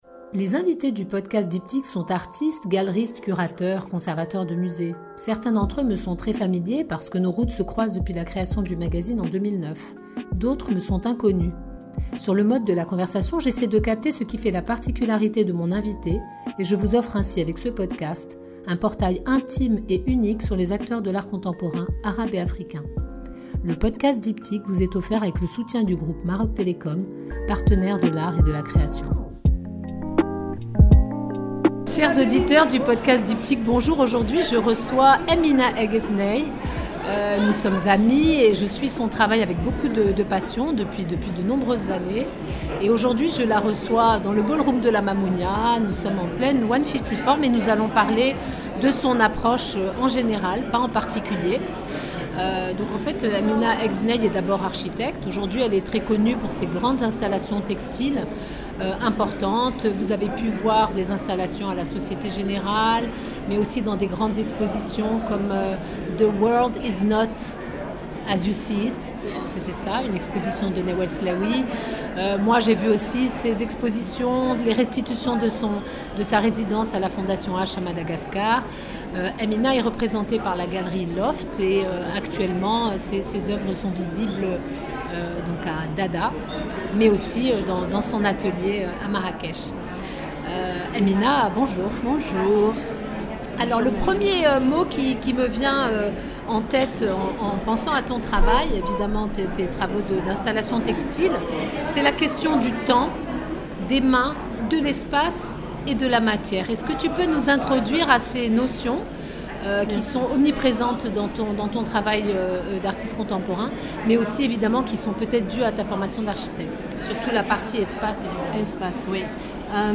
Cet épisode a été enregistré en Février 2024 à La Mamounia à l'occasion de la foire d'art contemporain africain 1-54, avec Maroc Télécom, partenaire des arts et de la création.